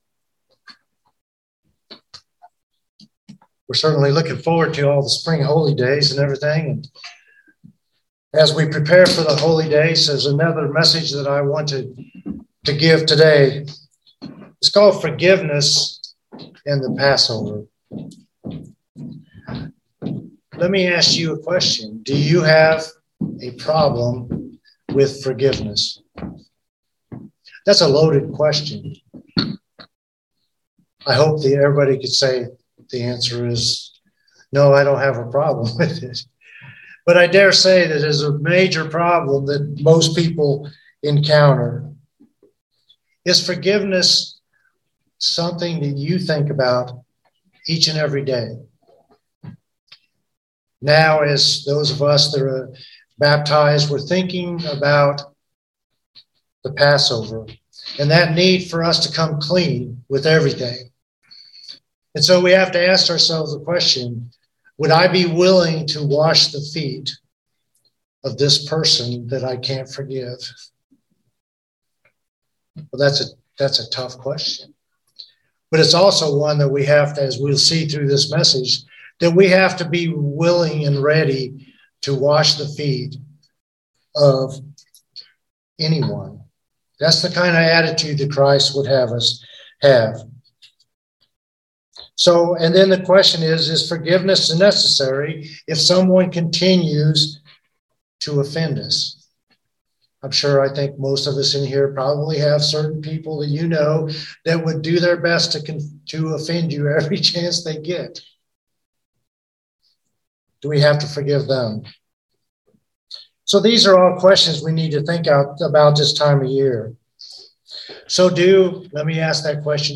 Sermons
Given in Central Georgia Columbus, GA